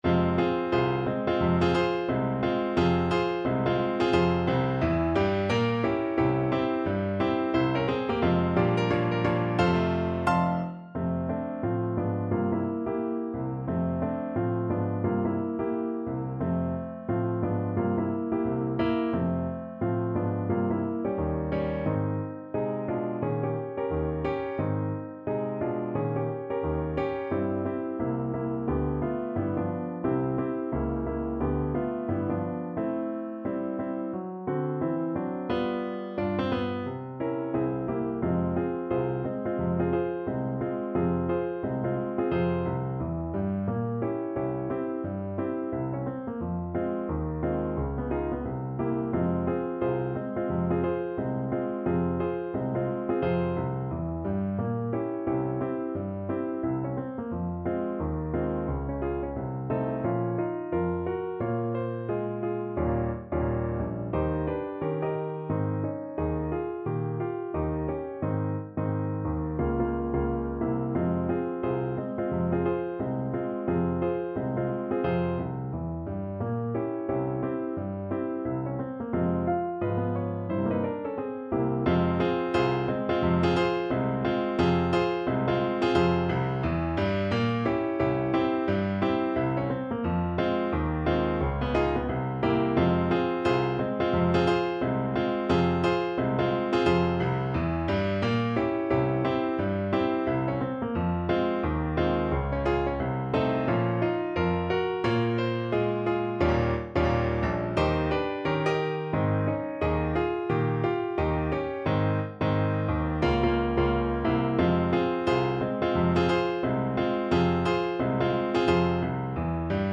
~ = 176 Moderato